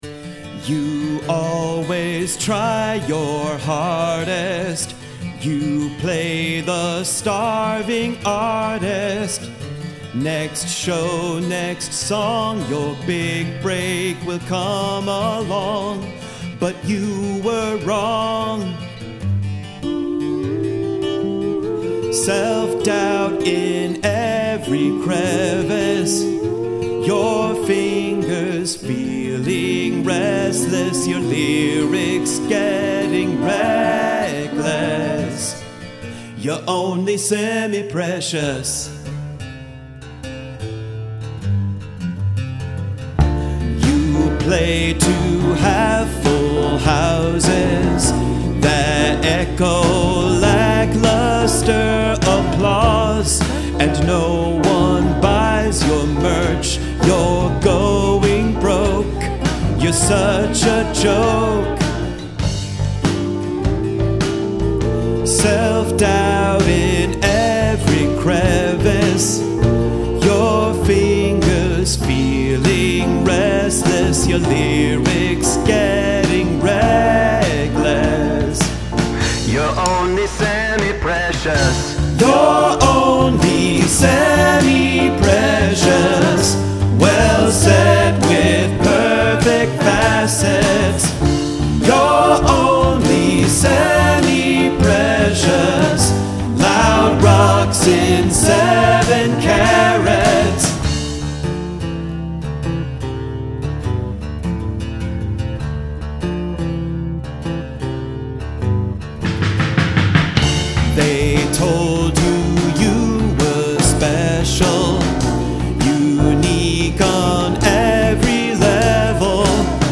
Gradual emphasis of repetitions